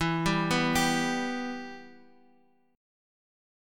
Em Chord
Listen to Em strummed